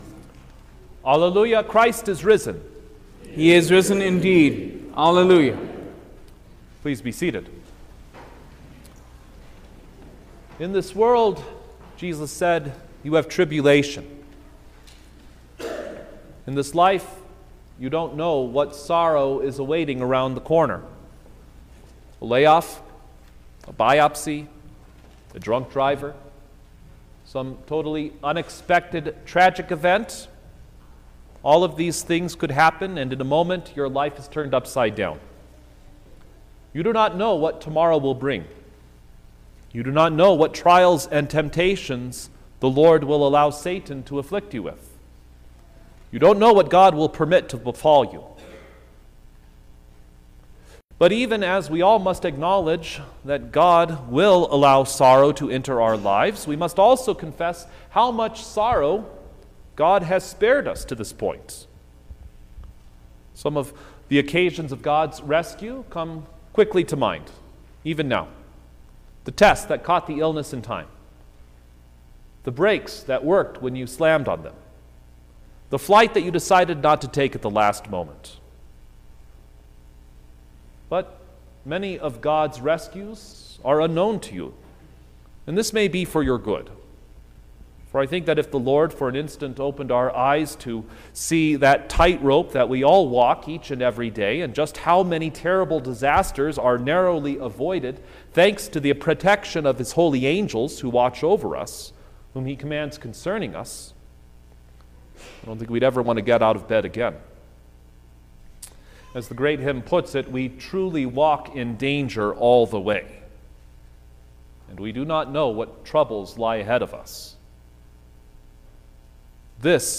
May-5_2024_Sixth-Sunday-of-Easter_Sermon-Stereo.mp3